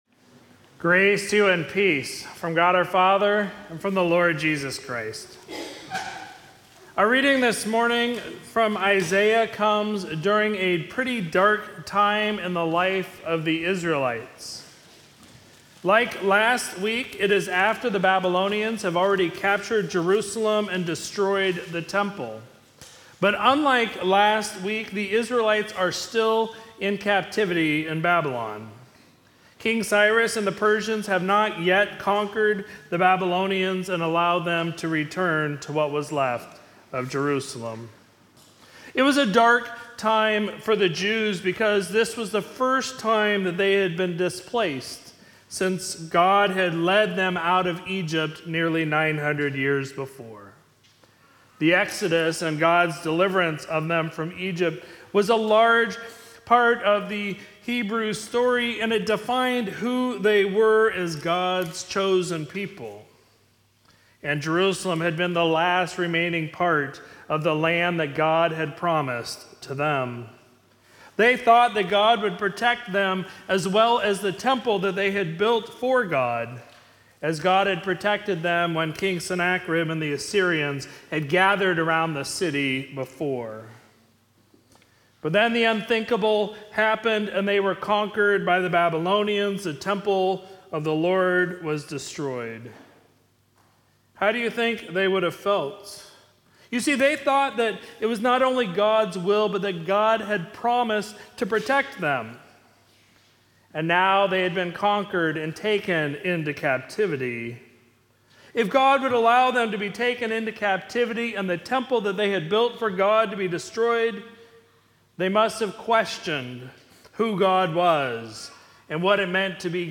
Sermon for Sunday, December 11, 2022